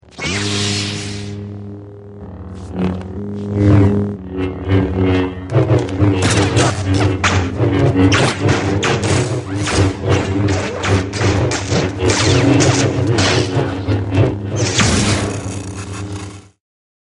sabrelaser.mp3